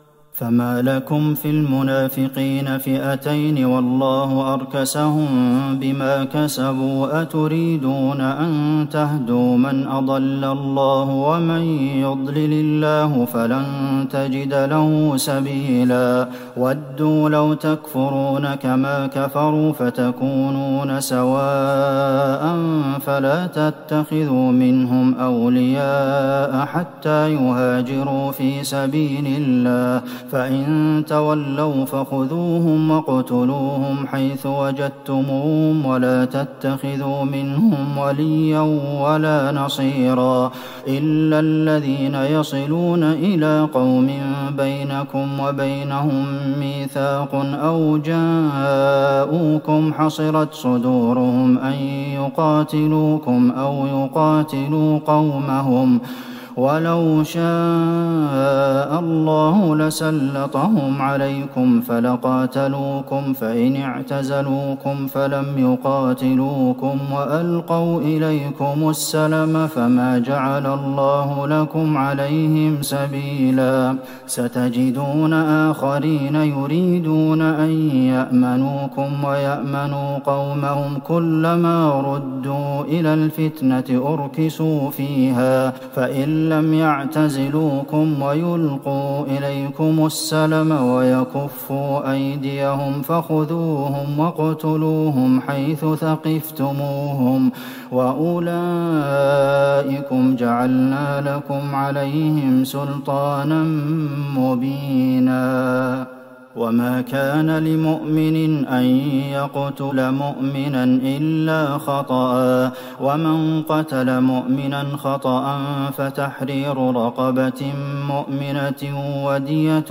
ليلة ٧ رمضان ١٤٤١هـ من سورة النساء { ٨٨-١٣٤ } > تراويح الحرم النبوي عام 1441 🕌 > التراويح - تلاوات الحرمين